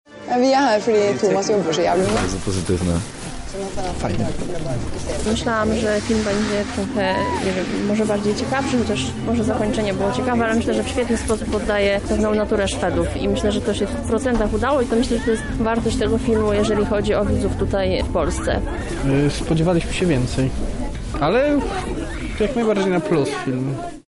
Jedną z wczorajszych propozycji była szwedzka produkcja pod tytułem „Turysta”. O swoich wrażeniach mówią widzowie.